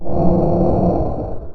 To have a little fun with our demonstration device, we modeled it on an outdoor air conditioner compressor unit and gave it sound effects.
4. Copy three sound effect files by right-clicking each of these links and saving to any convenient temporary location on your PC:
ac-off.wav